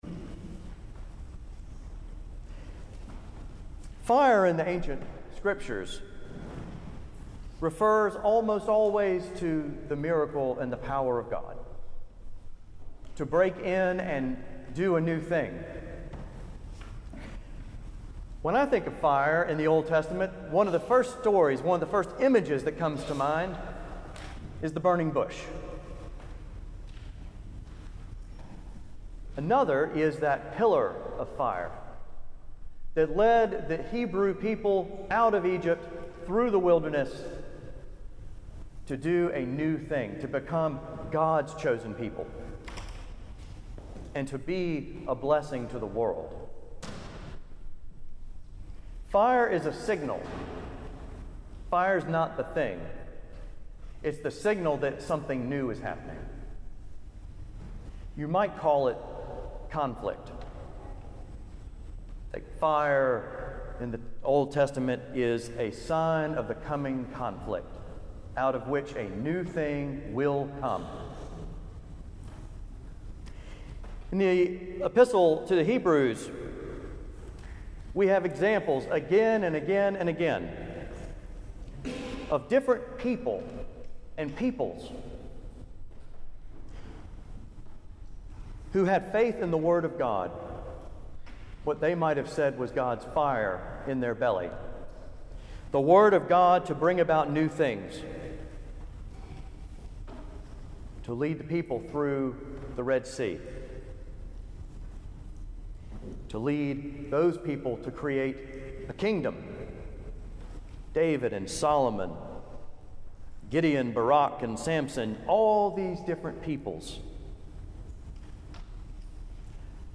Video Sermon begins at 20:48 Audio Sermon only